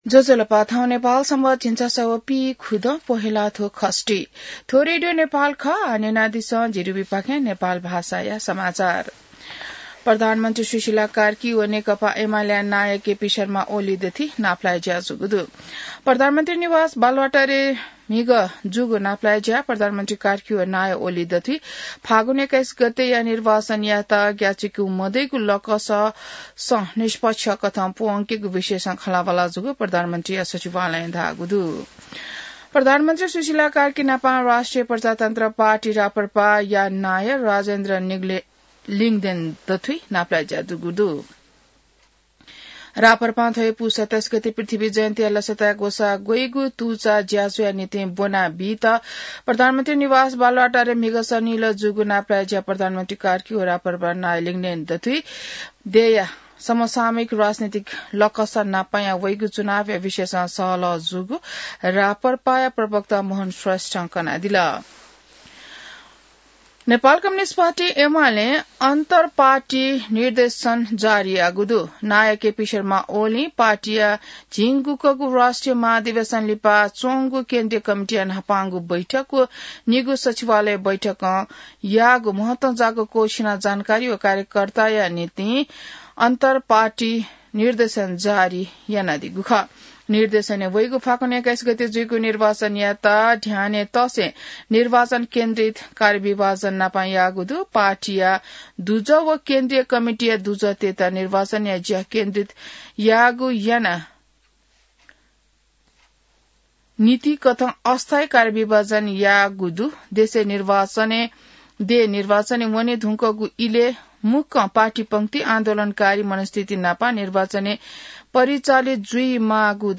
नेपाल भाषामा समाचार : २५ पुष , २०८२